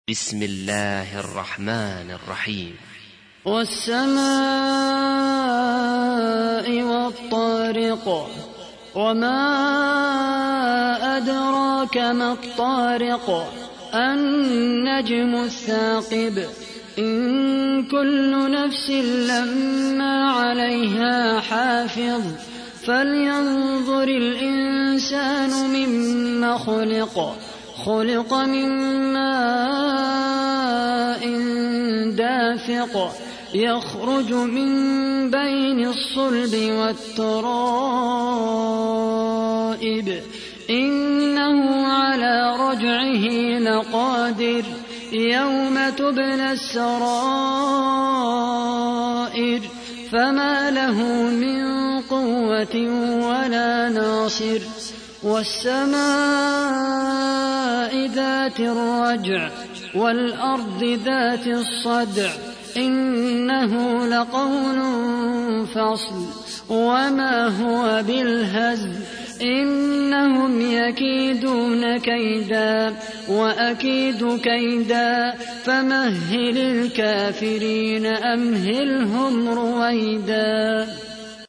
تحميل : 86. سورة الطارق / القارئ خالد القحطاني / القرآن الكريم / موقع يا حسين